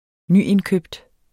Udtale [ -enˌkøbd ]